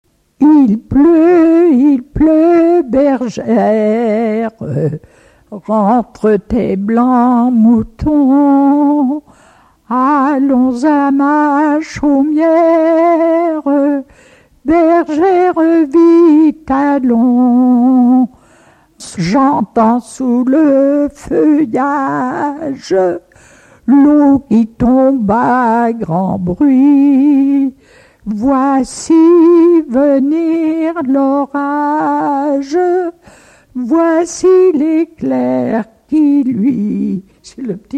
Ronde d'enfants
Pièce musicale inédite